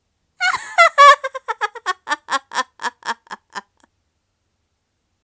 笑い声